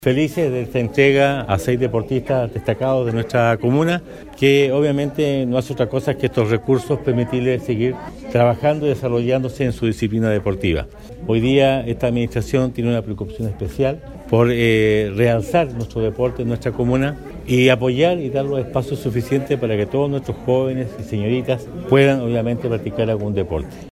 El alcalde Emeterio Carrillo mostró su satisfacción por la entrega de los recursos a los jóvenes deportistas.